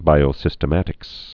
(bīō-sĭstə-mătĭks)